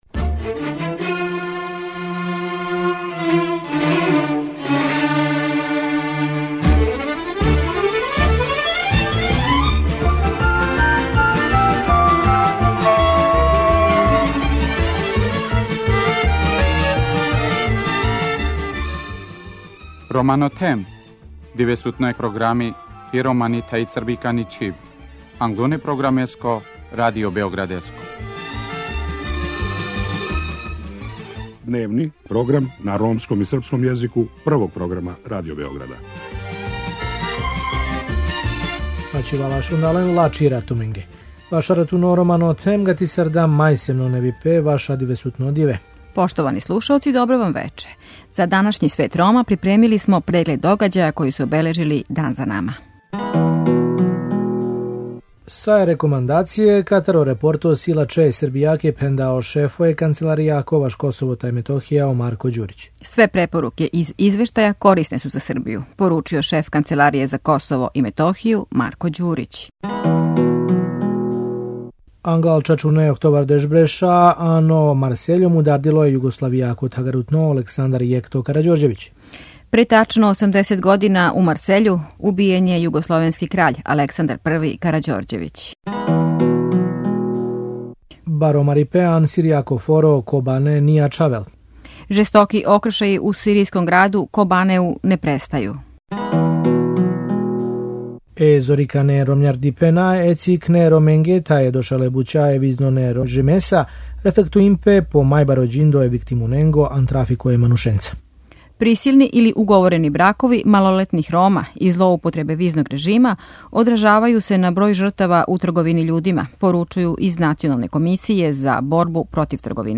У Свету Рома и данас слушамо ромске лидере из Србије који су први пут од кад постоји модерна ромска политичка елита међусобно суочени у једној медијској дебати.
У на моменте жучној расправи, они објашњавају зашто афирмативне мере за упис ромских студената на универзитете у Србији, до данас нису јасно дефинисане, зашто и овде имамо разлку између Рома у покрајини и Рома у остатку земље, и ко је крив што се од неограниченог броја ромских бруцоса дошло до квоте од само 2% која је сада заједницка за Роме и остале маргинализоване друштвене групе.